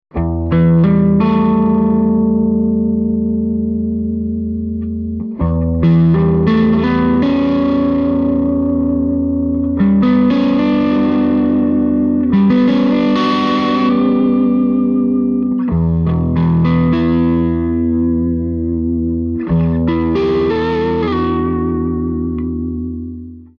With the EL-34s, the SST-30 takes on a more British tone without losing the richness of the midrange.